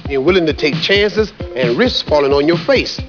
To hear Mr. T answer, just click the responses.